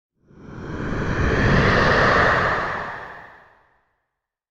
دانلود صدای باد 68 از ساعد نیوز با لینک مستقیم و کیفیت بالا
جلوه های صوتی